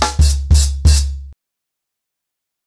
TrackBack-90BPM.17.wav